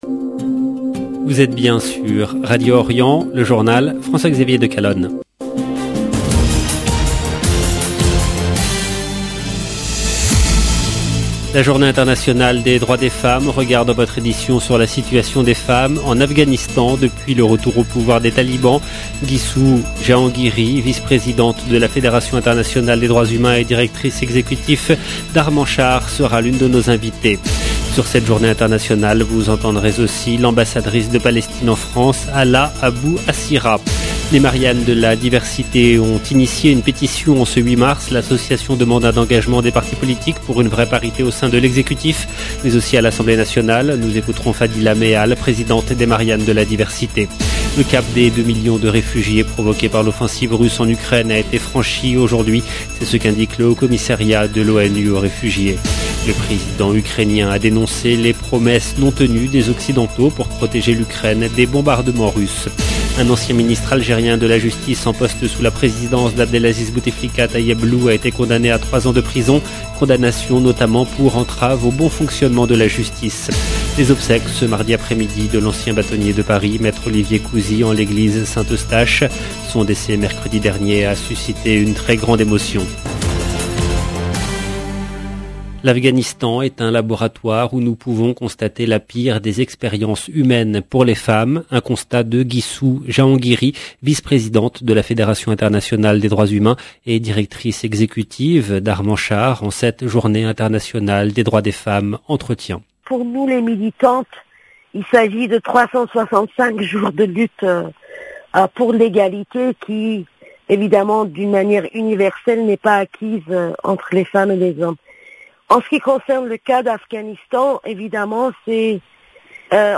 Ukraine LB JOURNAL EN LANGUE FRANÇAISE 0:00 16 min 3 sec 8 mars 2022
Sur cette journée internationale, vous entendrez l’ambassadrice de Palestine en France, Hala Abou-Hassira.